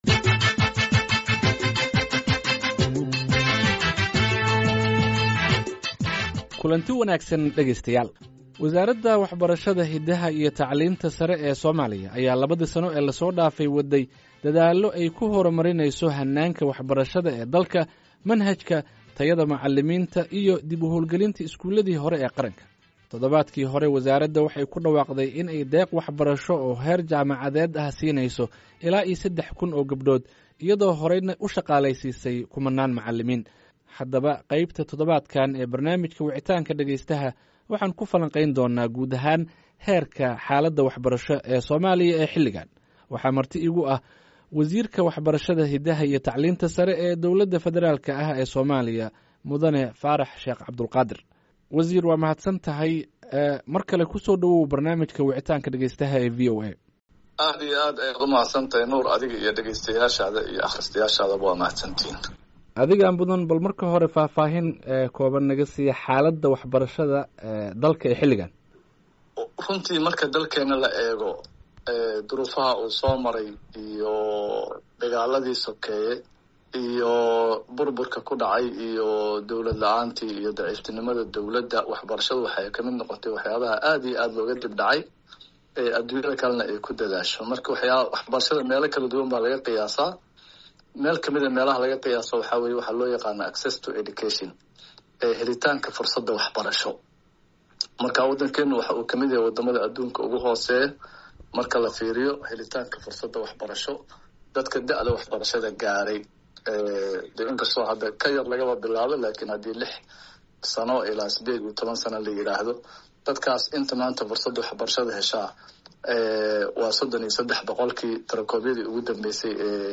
Wicitaanka: Wasiirka Waxbarashada Soomaaliya Faarax Cabdulqaadir